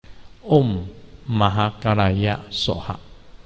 Mantra